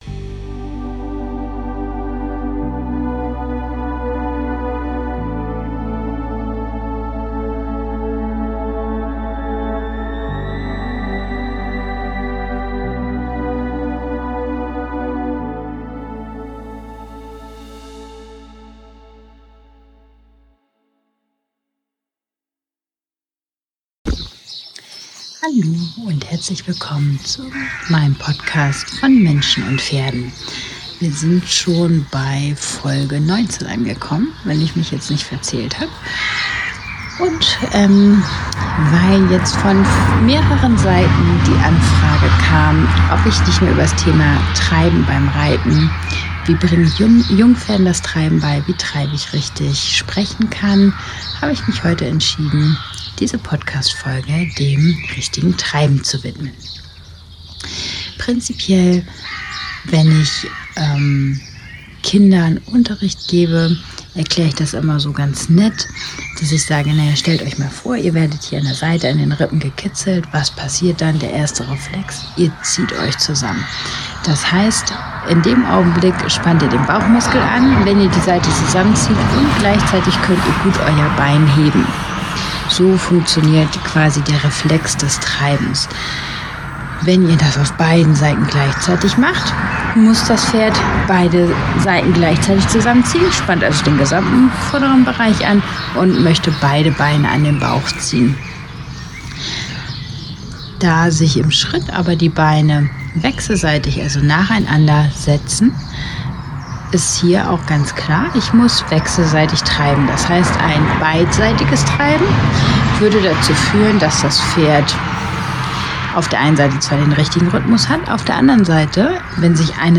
Diese Folge hat einige Nebengeräusche da ich sie aus Zeitgründen ein bisschen improvisiert aufnehmen musste.